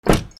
110 車のドアを閉める
/ E｜乗り物 / E-10 ｜自動車